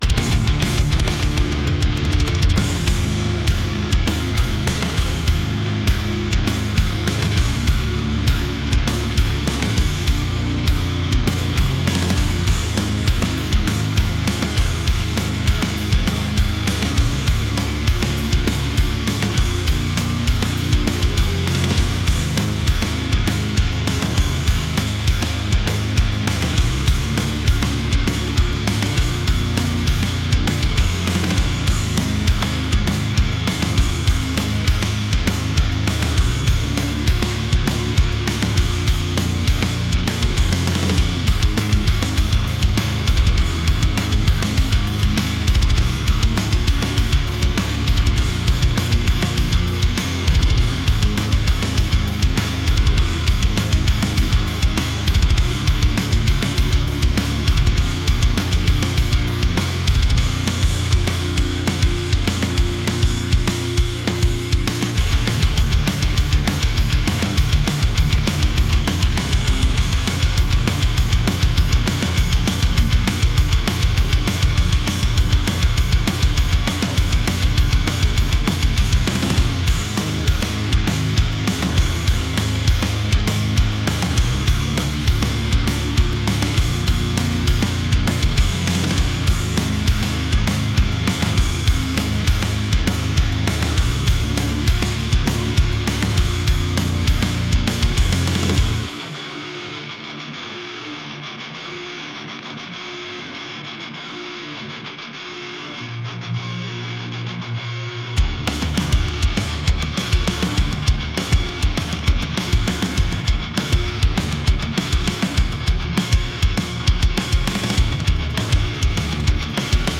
metal | heavy | aggressive